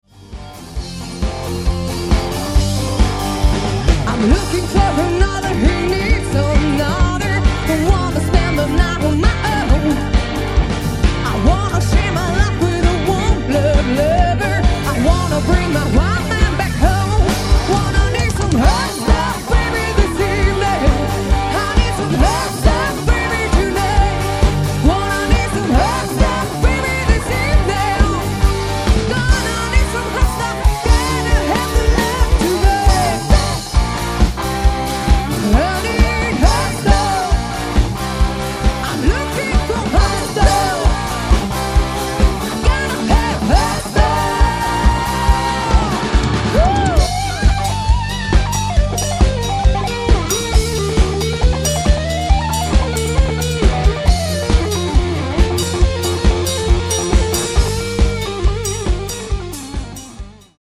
• Rockband
• Coverband